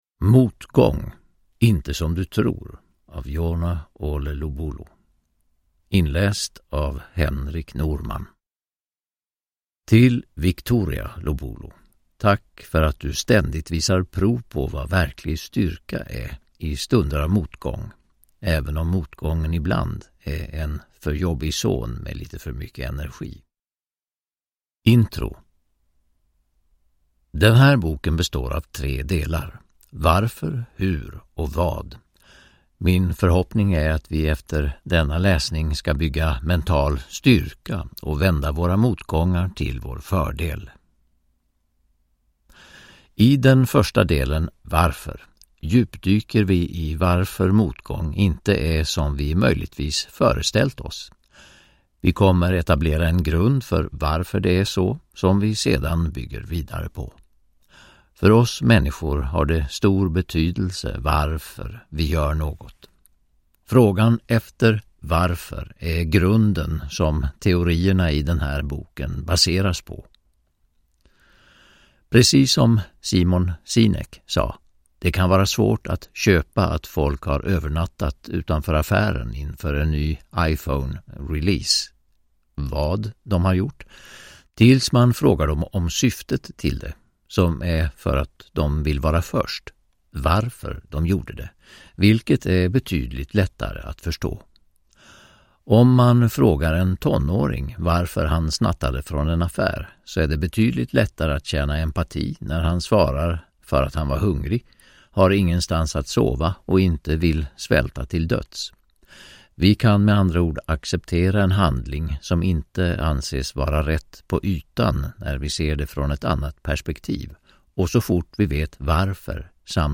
Motgång - Inte som du tror – Ljudbok – Laddas ner